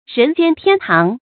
人間天堂 注音： ㄖㄣˊ ㄐㄧㄢ ㄊㄧㄢ ㄊㄤˊ 讀音讀法： 意思解釋： 指人世間極美好的地方。